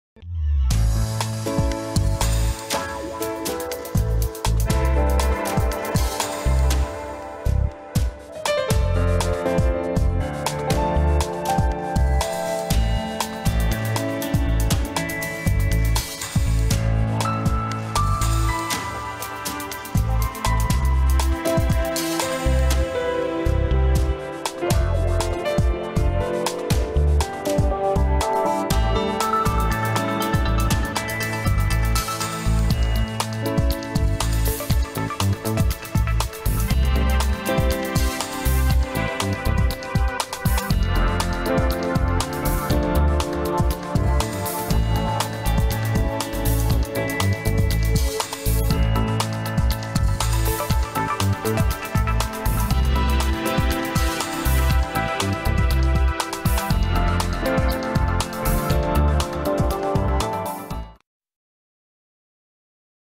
Атмосферная музыка для прогноза погоды